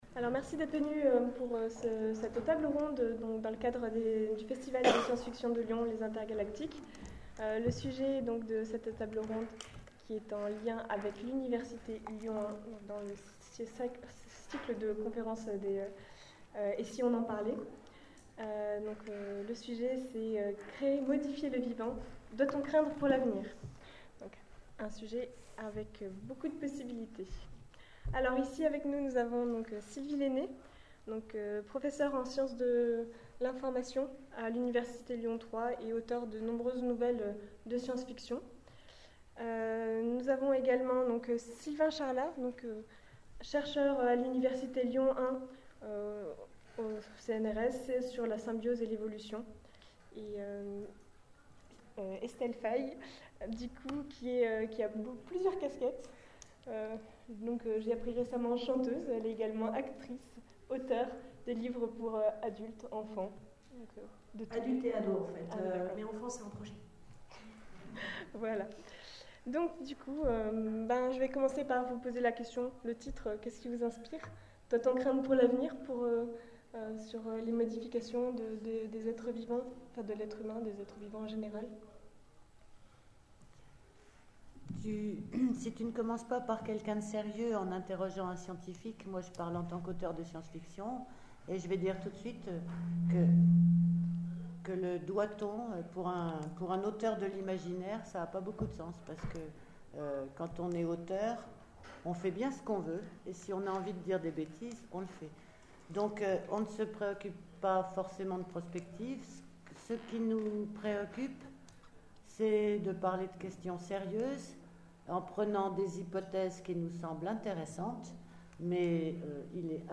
Les intergalactiques 2014 : conférence Modifier / Fabriquer le vivant : doit-on imaginer le pire pour l’avenir ?